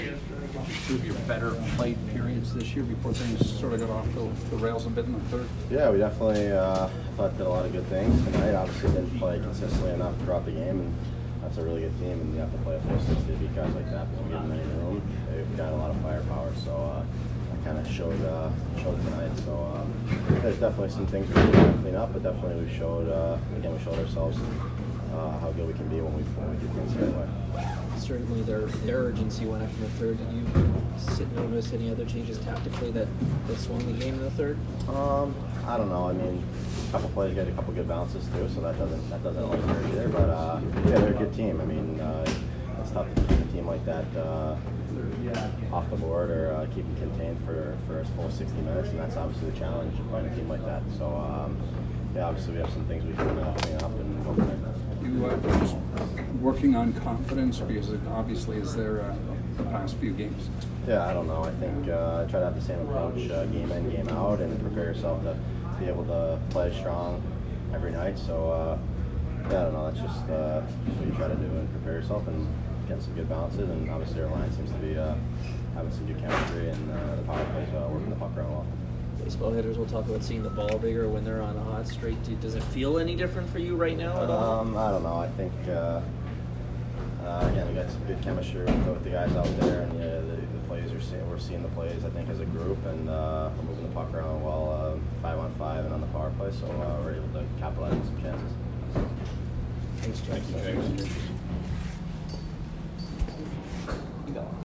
James Van Riemsdyk post-game 3/20